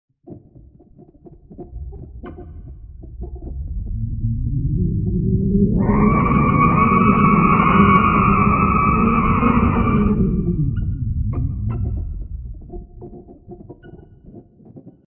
Звучание туманности в записи телескопа Хаббл